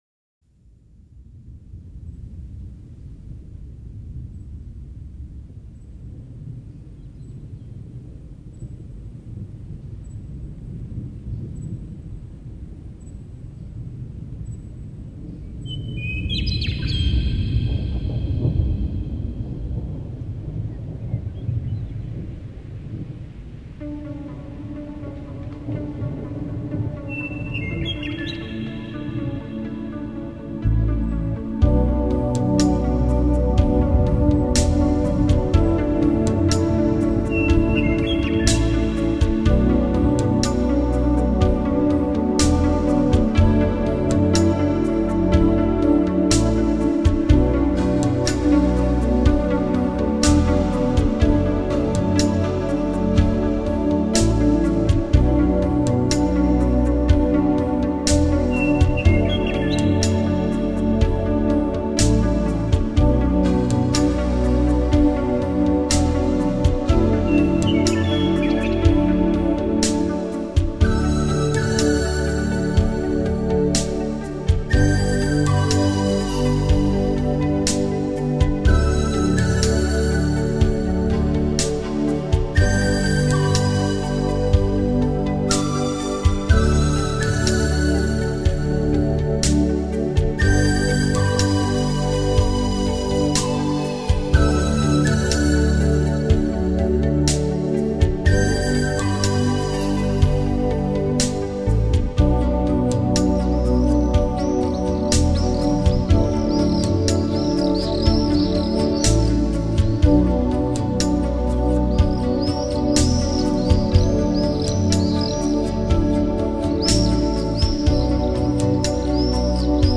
全碟大量的使用了直笛和排萧的吹奏，键盘及敲击乐器等传统南美乐器，加上隐隐的和声，揉出一派纯真的南美风格。